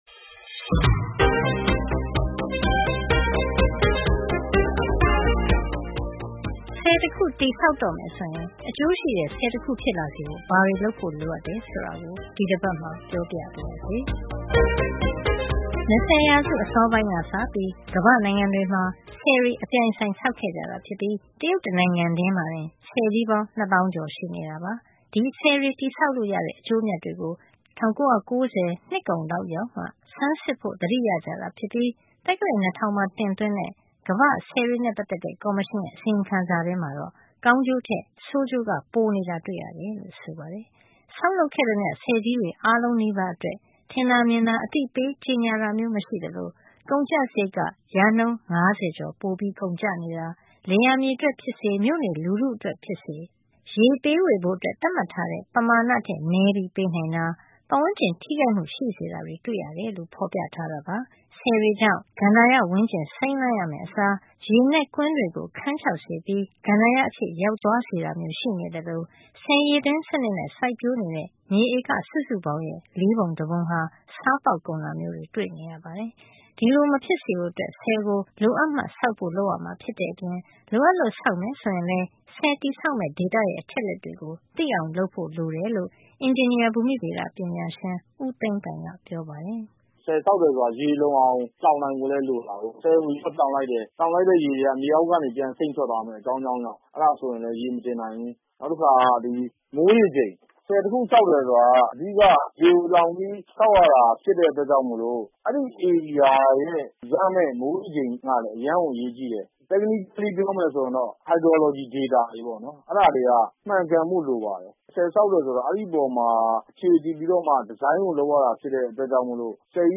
အင်ဂျင်နီယာ ဘူမိဗေဒပညာရှင်